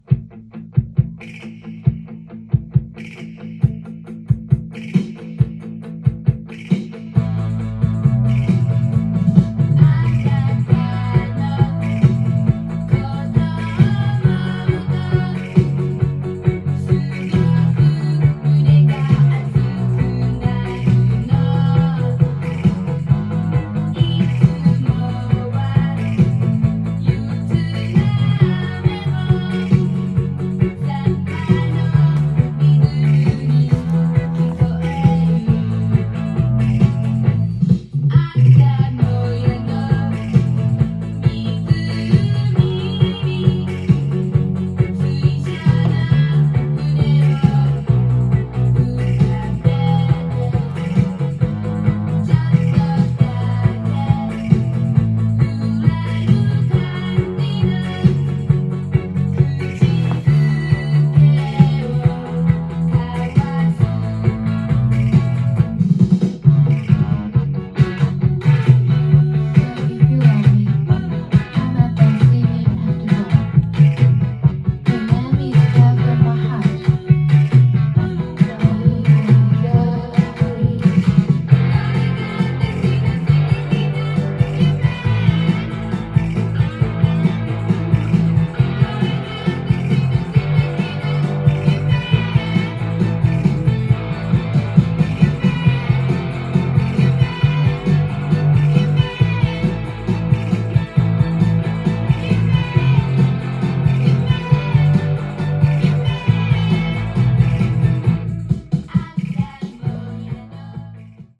店頭で録音した音源の為、多少の外部音や音質の悪さはございますが、サンプルとしてご視聴ください。
テクノ＋R&R